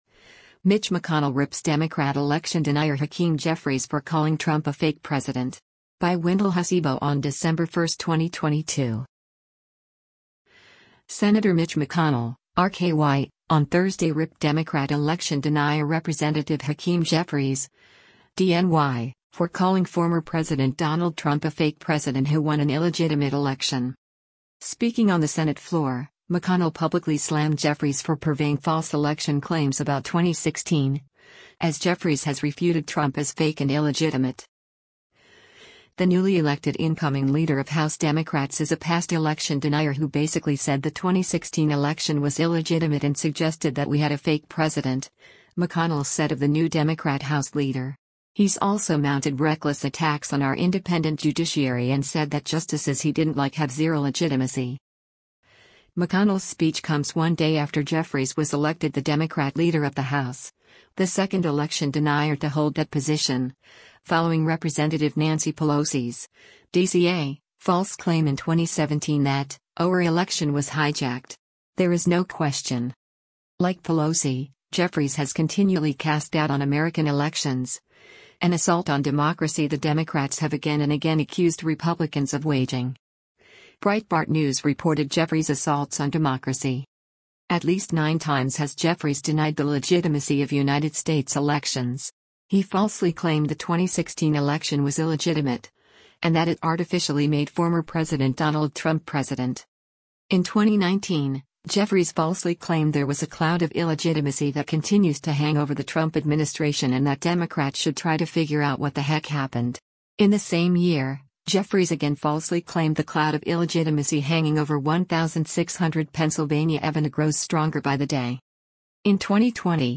Speaking on the Senate floor, McConnell publicly slammed Jeffries for purveying false election claims about 2016, as Jeffries has refuted Trump as “fake” and “illegitimate.”